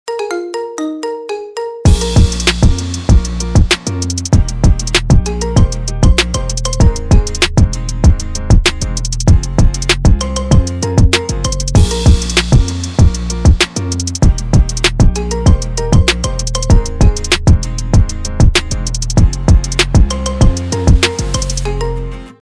Kategori Marimba